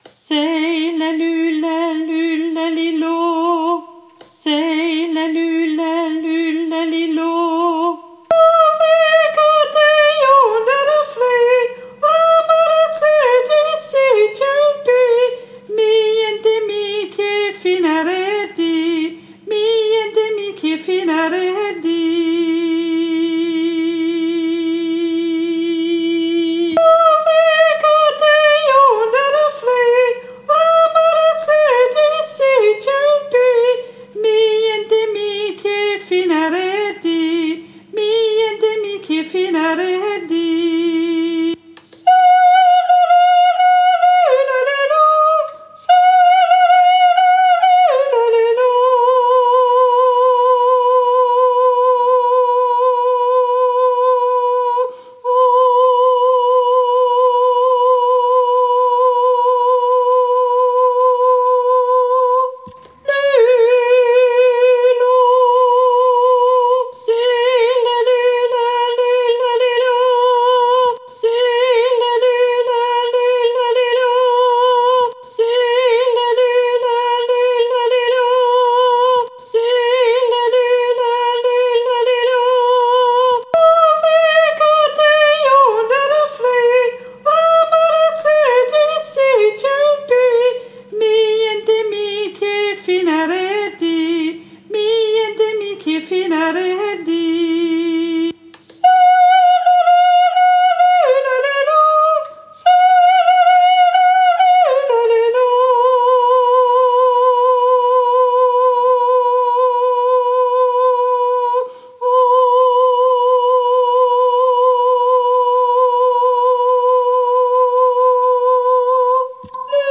Tenor 1